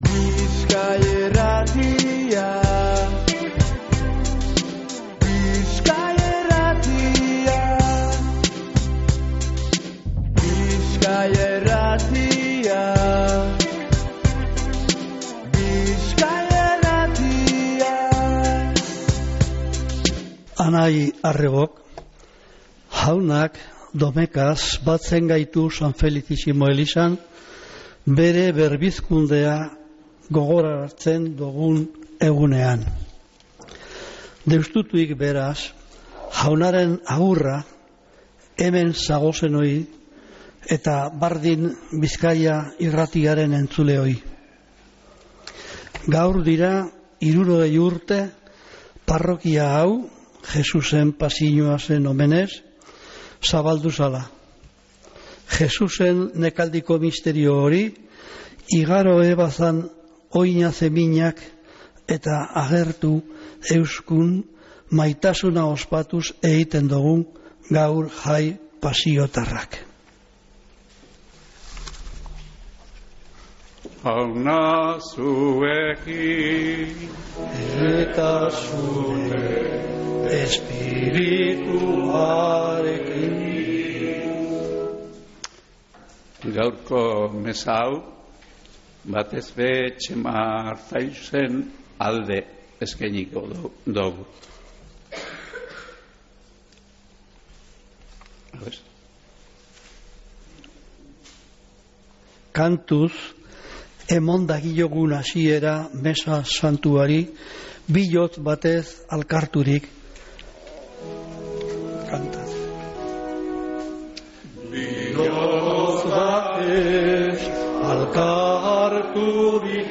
Mezea zuzenean San Felicisimotik